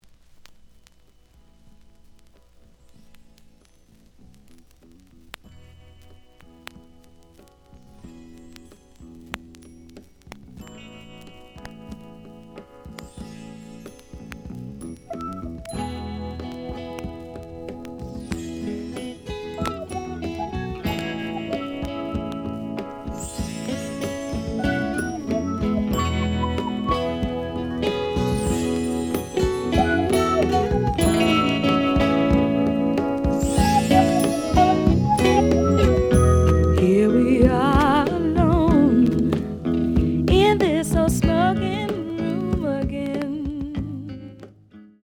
The audio sample is recorded from the actual item.
●Genre: Funk, 70's Funk
Slight noise on both sides.)